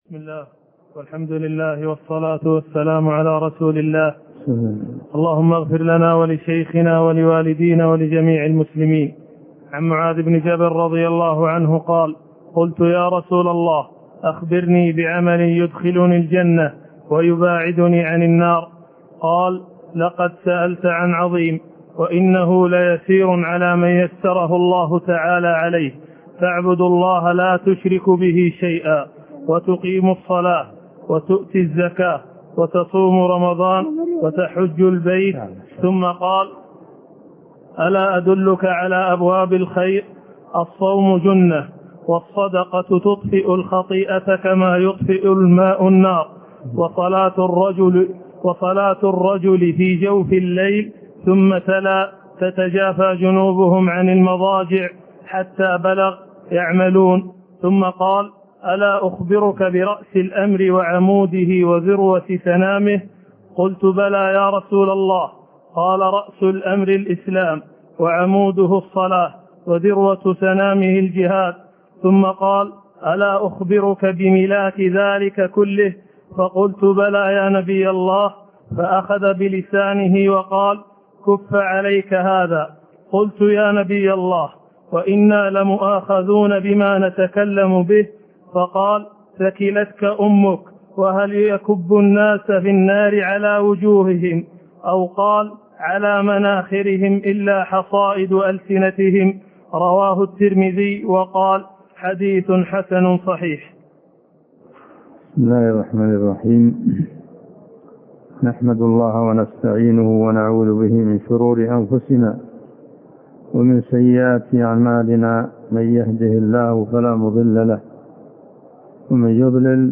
عنوان المادة الدرس (21) شرح الأربعين النووية تاريخ التحميل الأحد 22 يناير 2023 مـ حجم المادة 29.33 ميجا بايت عدد الزيارات 238 زيارة عدد مرات الحفظ 69 مرة إستماع المادة حفظ المادة اضف تعليقك أرسل لصديق